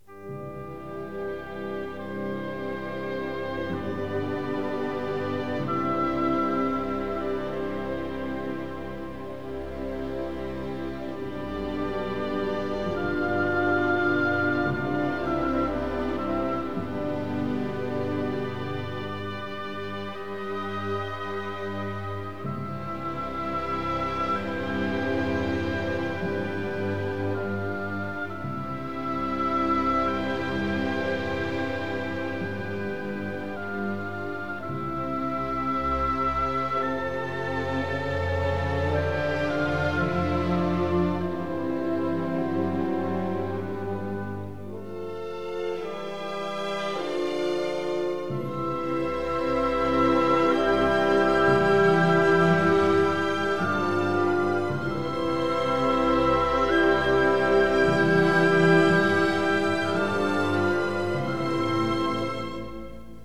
1960 stereo recording